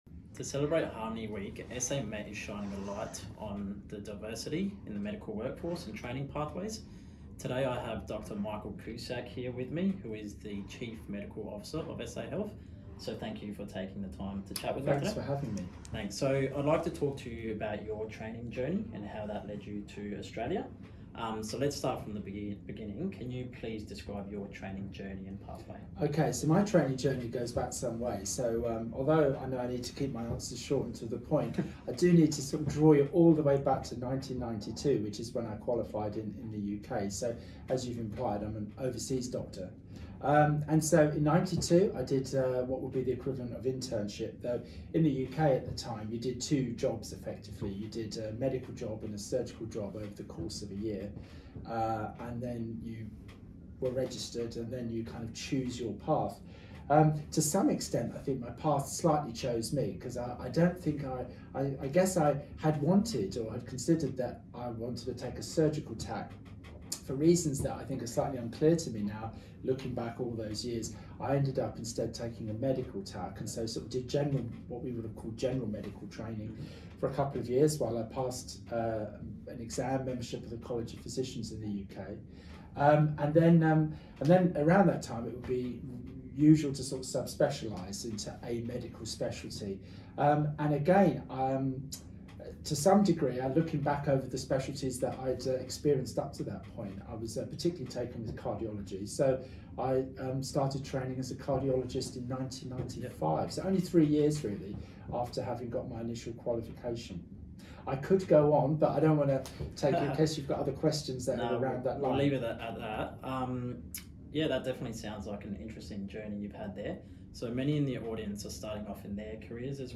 The Chief Medical Officer of South Australia, Dr Michael Cusack, speaks about his medical training pathway, challenges overcome and the difference between United Kingdom training pathway versus Australian training pathway.
Interview-with-CMO-Audio.mp3